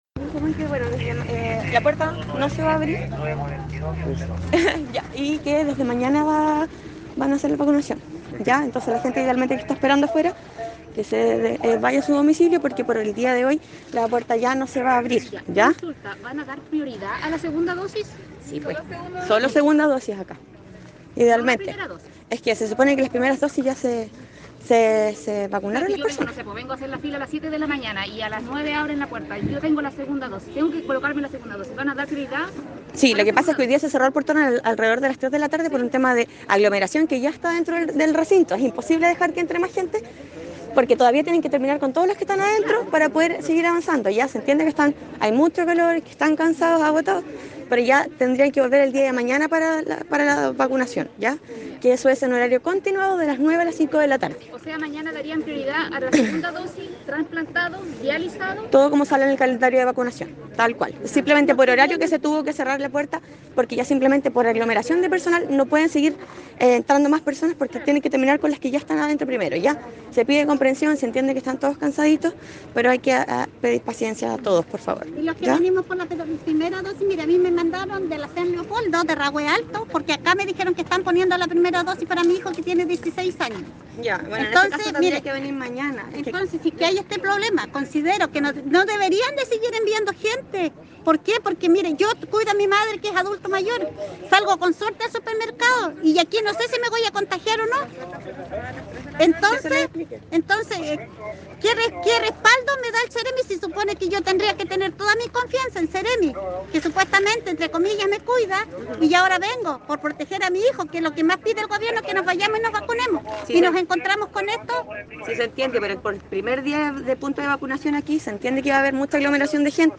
Así lo explicó una funcionaria del personal de cuadrillas sanitarias de la Seremi a algunas personas que estaban en las afueras del recinto, confirmando, además que el día de mañana se atenderá de manera continuada entre las 9 y las 17 horas.